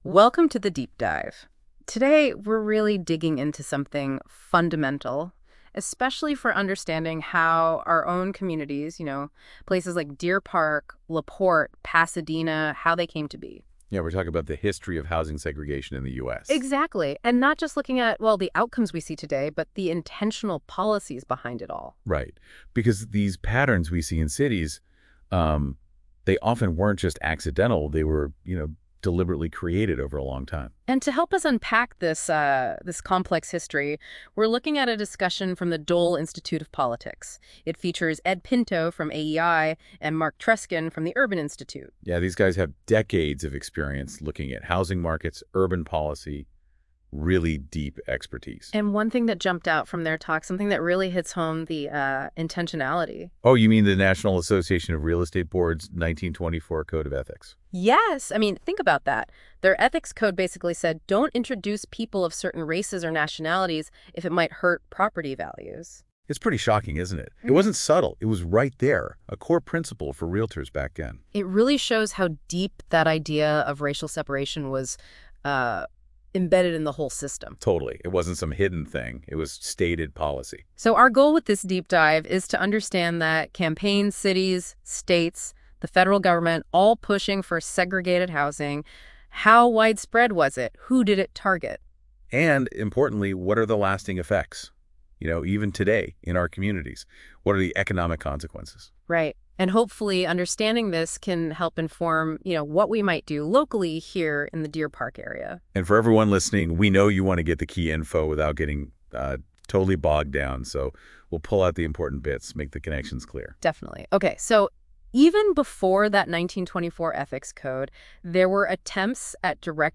🏘 Segregation, Race, and City Policy: A Discussion #421 | Think And Act Locally